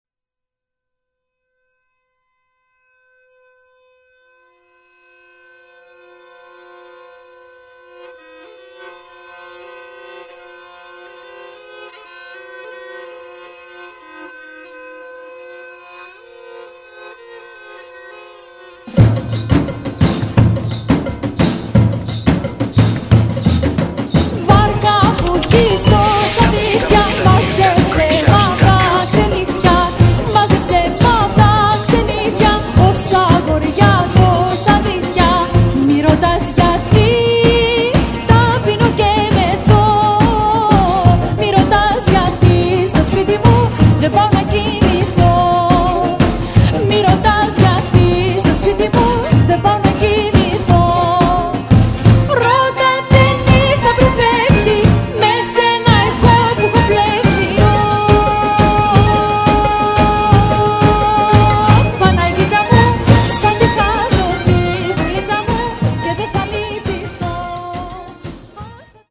Her voice is distinct and recognized throughtout Europe.